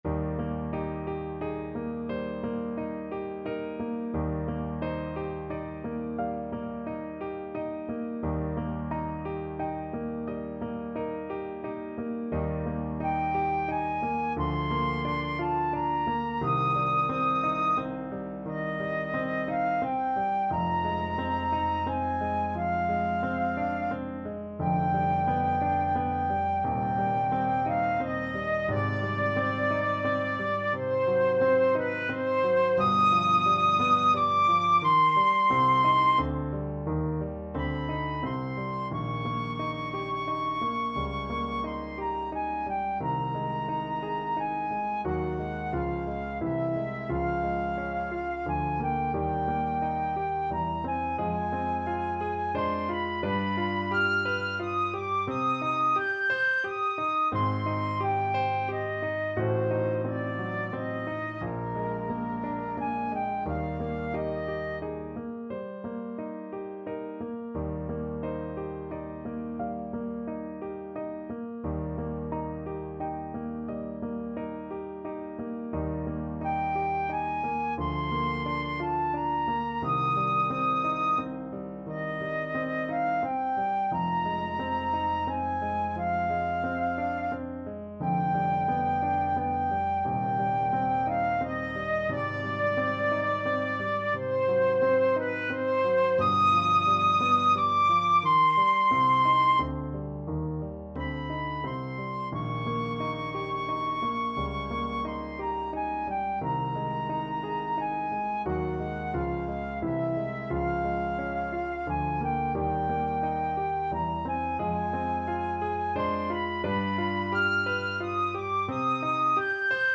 Classical Fanny Mendelssohn Die Mainacht from 6 Lieder, Op.9 Flute version
Flute
6/4 (View more 6/4 Music)
Eb major (Sounding Pitch) (View more Eb major Music for Flute )
~ = 88 Andante
Classical (View more Classical Flute Music)